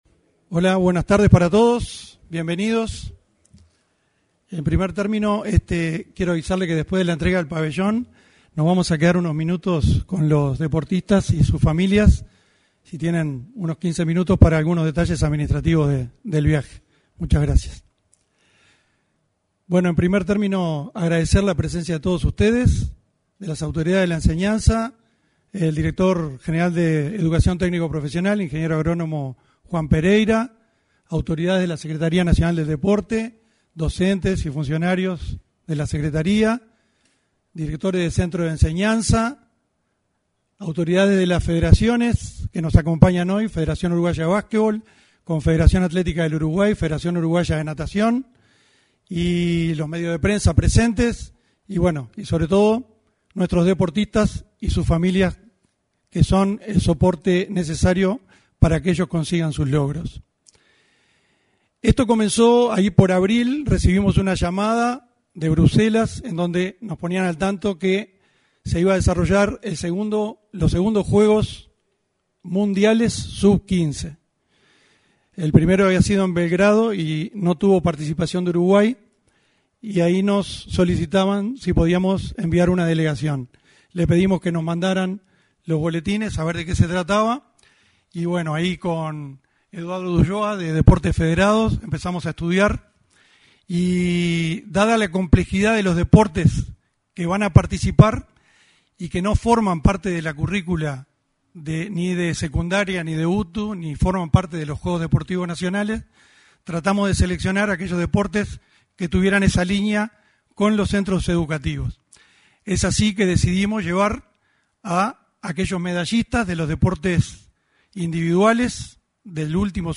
Este jueves 10, en el salón de actos de la Torre Ejecutiva, se entregó el pabellón nacional a los jóvenes que participarán en los Juegos Deportivos Escolares Mundiales.